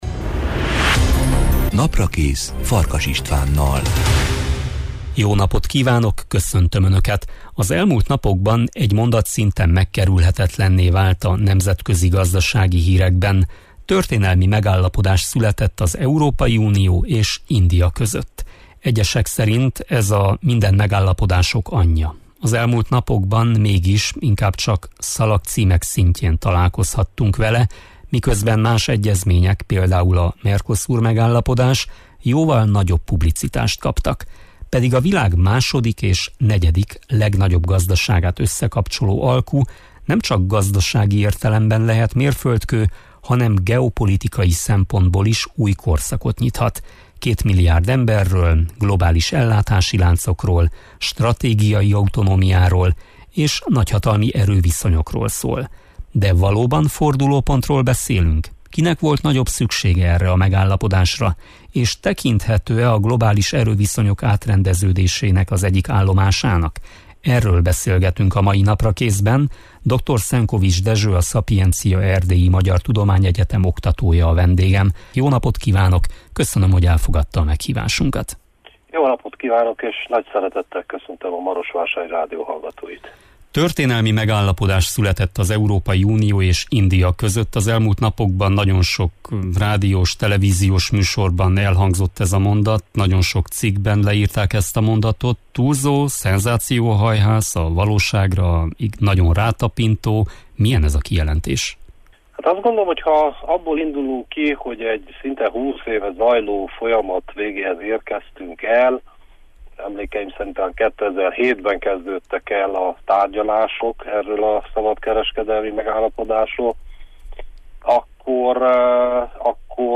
Erről beszélgetünk a Naprakészben.